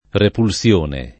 repulsione [ repul SL1 ne ]